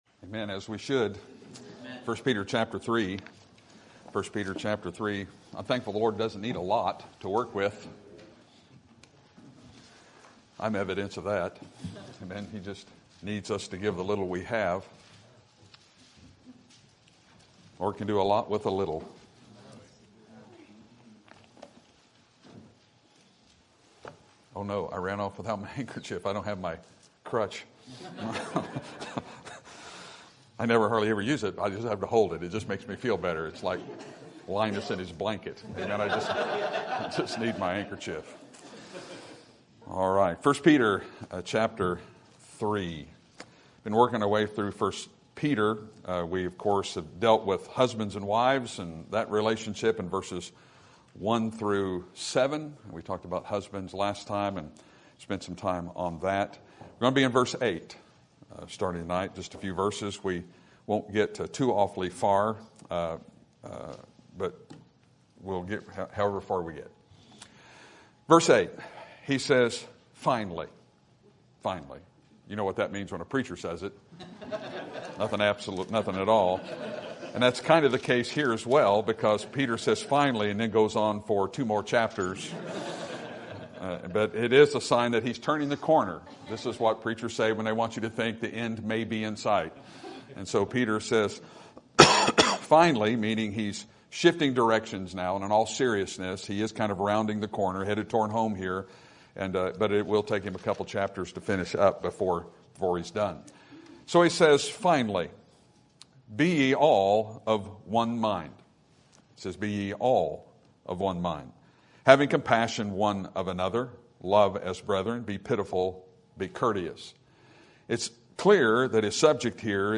Sermon Topic: Book of 1 Peter Sermon Type: Series Sermon Audio: Sermon download: Download (23.21 MB) Sermon Tags: 1 Peter Church Mind One